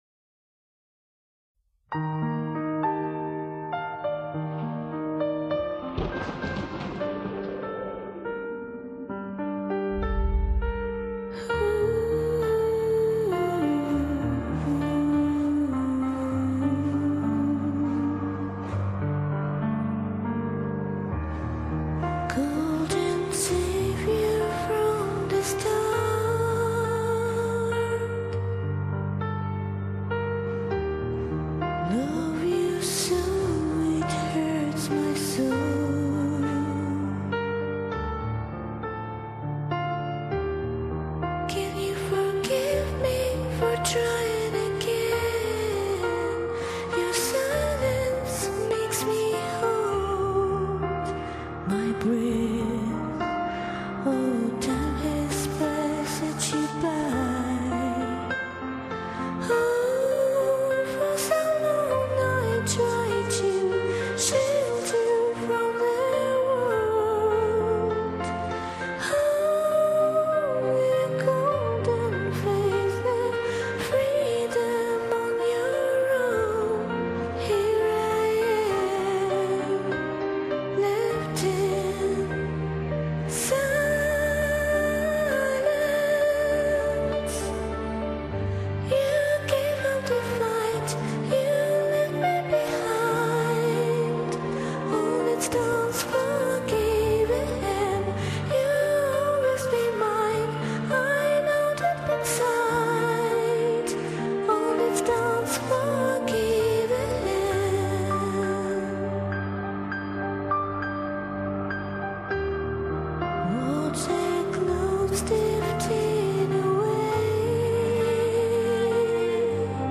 Голоса сказочно красивые, серебрянные!